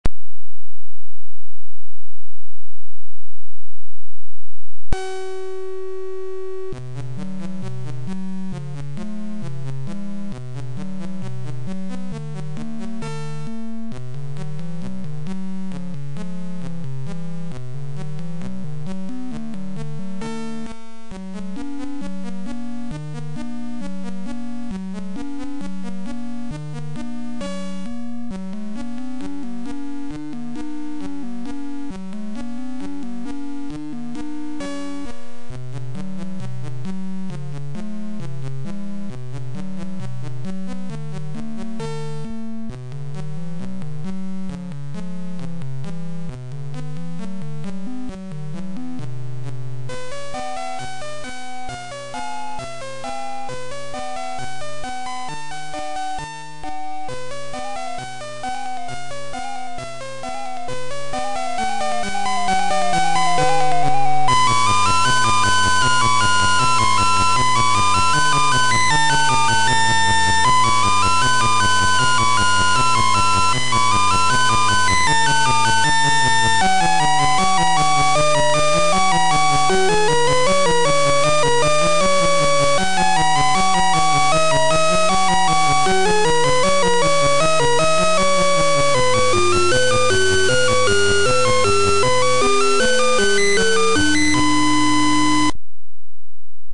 This is the version from the game